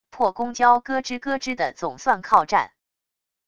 破公交咯吱咯吱的总算靠站wav音频